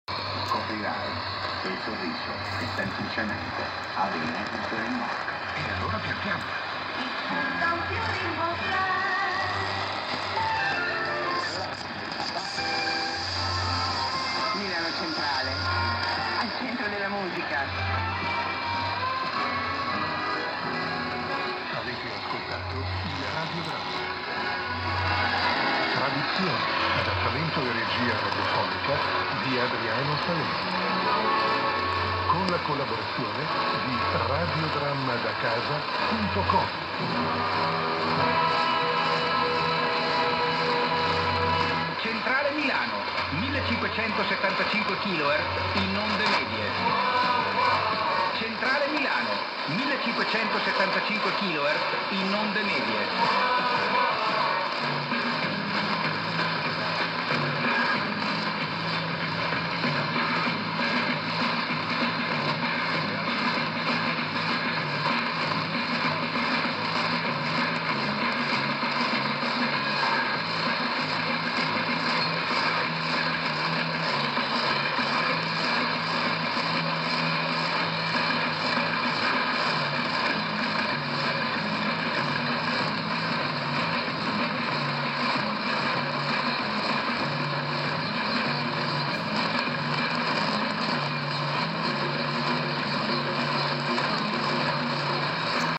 Ricevitore adoperato un Panasonic RFB-65 con antenna in ferrite interna. ma, per l'ascolto delle onde medie, ho usato un'antenna a loop in ferrite esterna che trasferisce un segnale per induzione un segnale maggiore a quella interna, limitando il rumore tipico della banda.
Tutti gli ascolti sono corredati dalla relativa clip audio che fornisce la qualità della ricezione.
Centrale Milano una stazione radio che trasmette da Alessandria su 1575 kHz con 750 W di potenza. L'ascolto è soggetto a fading profondo e lento che porta a volte alla prevalenza di questa stazione sulla SER Cordoba della catena spagnola SER.